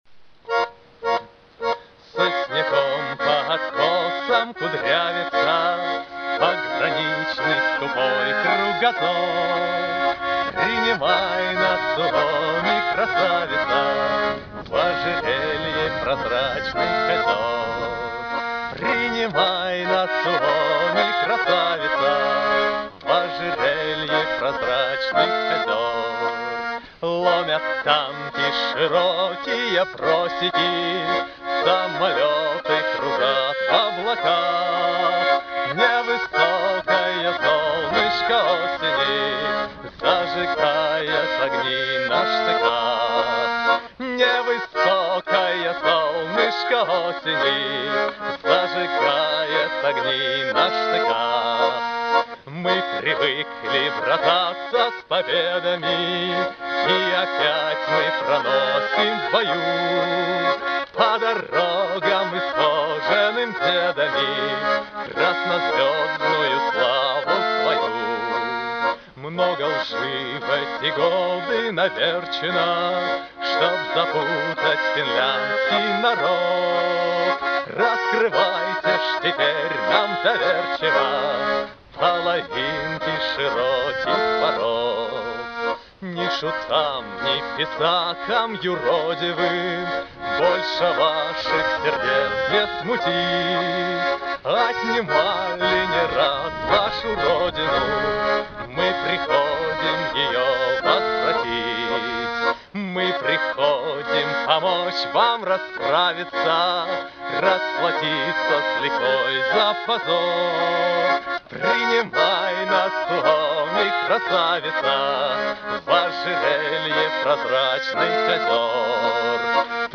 Современное исполнение известной песни.